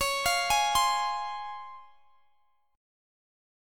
Listen to C#M7 strummed